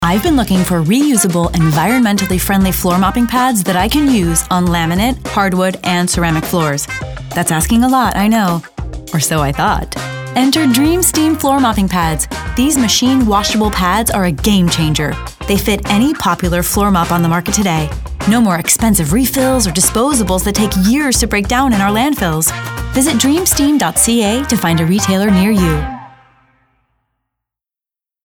announcer, classy, confident, conversational, cool, genuine, girl-next-door, perky, upbeat, young